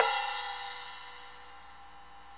Index of /90_sSampleCDs/Club-50 - Foundations Roland/CYM_xCrash Cyms/CYM_xSplash Cyms